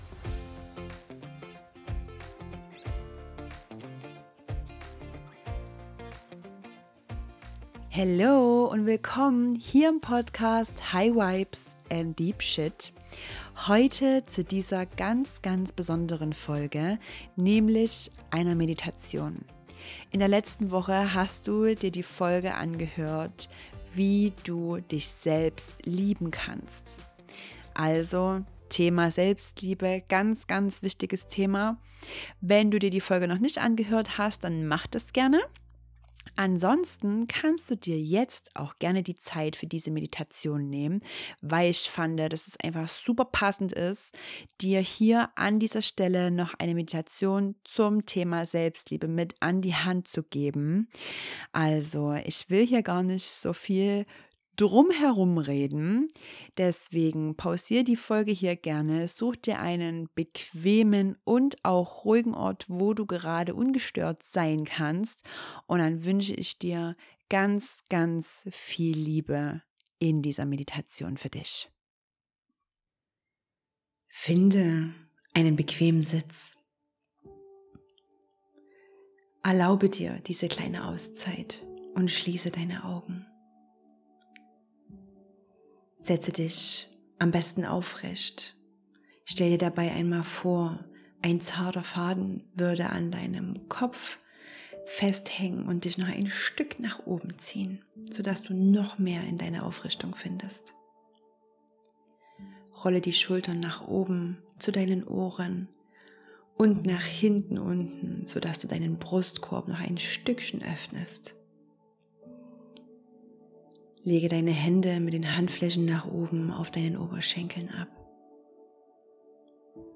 #7 MEDITATION für mehr Selbstliebe ~ High Vibes & Deep Shit Podcast